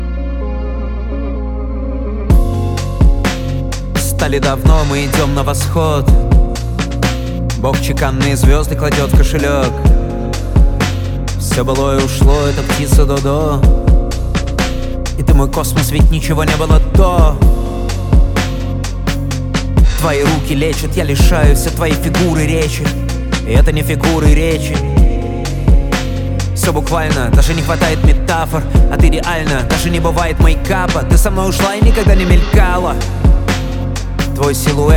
Rap Hip-Hop